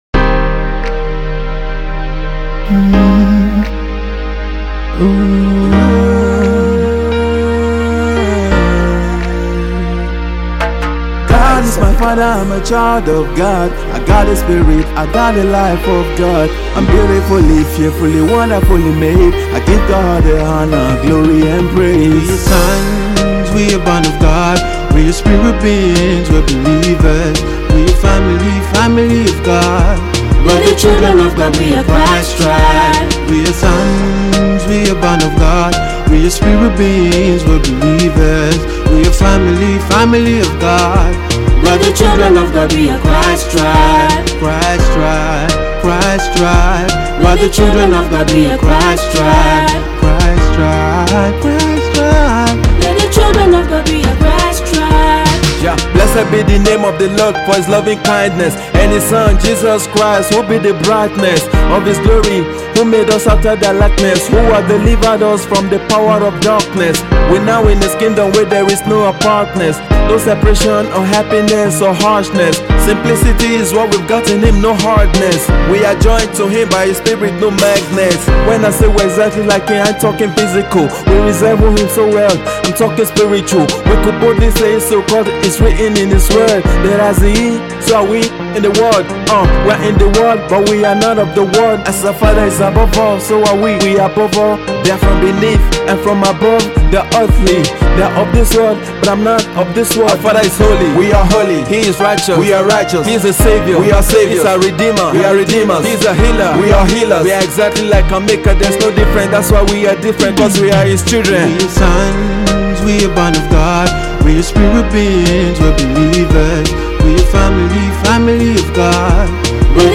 Nigerian multi-talented Gospel rapper  and recording artist